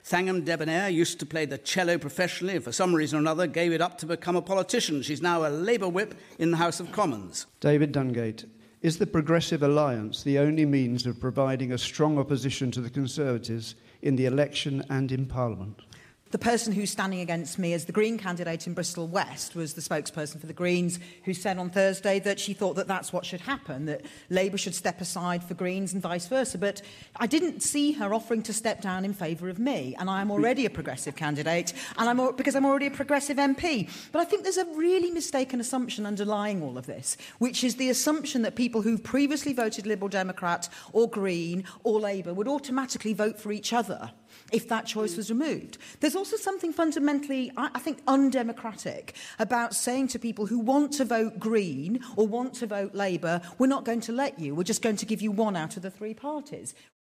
Thangam Debbonaire, MP for Bristol West, speaking on Radio 4 'Any Questions?'